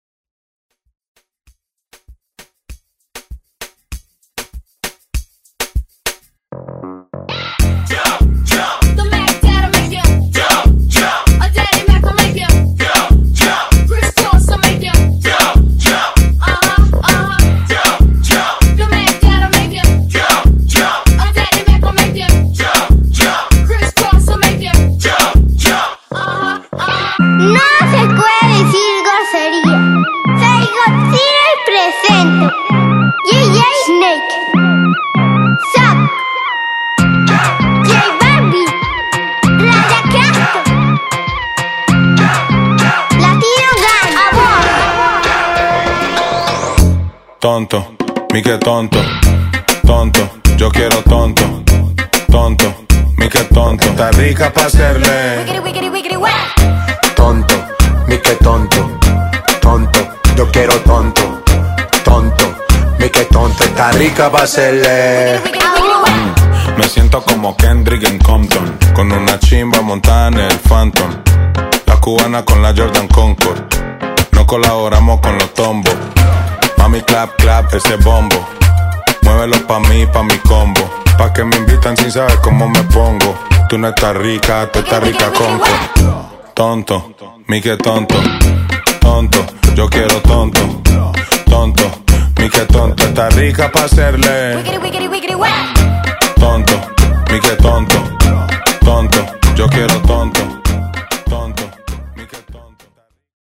BPM: 98 Time